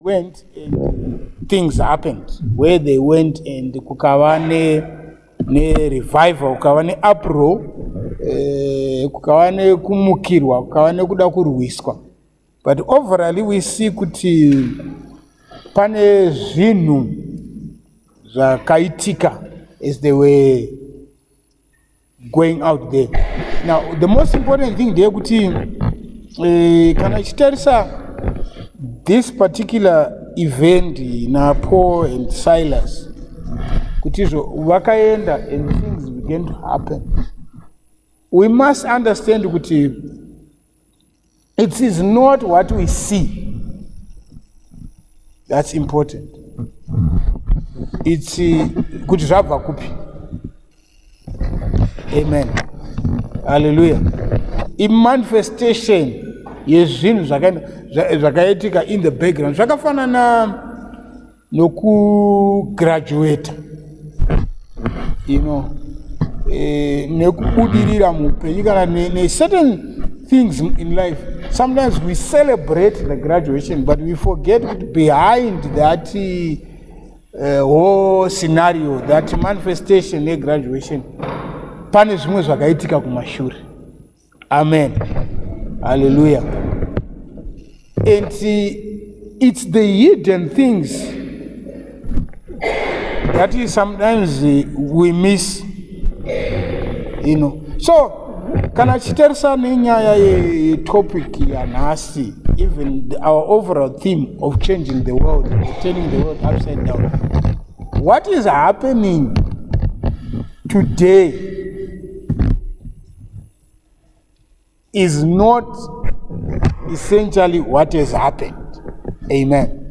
Sermons | Barnabas Leadership Outreach Center